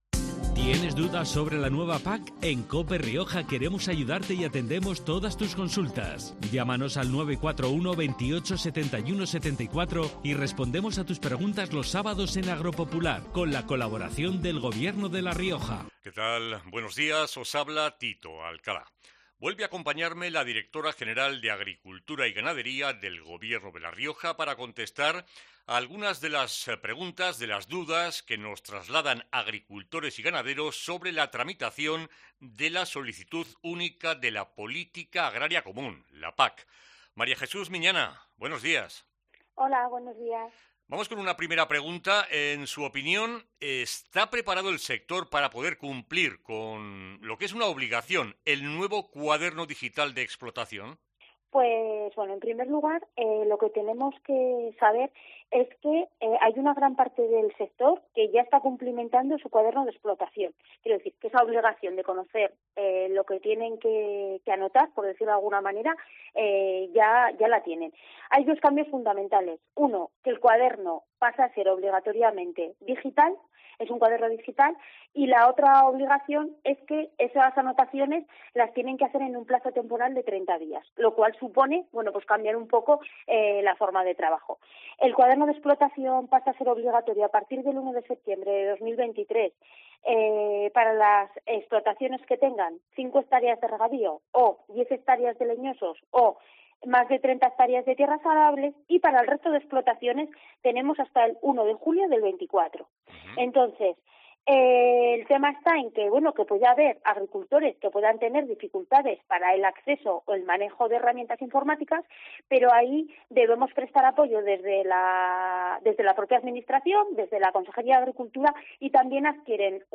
En el tercer programa, la directora general de Agricultura y Ganadería, María Jesús Miñana, ha respondido a una nueva pregunta que ha llegado hasta nuestra redacción: ¿Está preparado el sector para poder cumplir con la obligación del nuevo cuaderno digital de explotación?